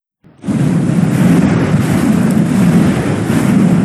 Flamethrower Start.wav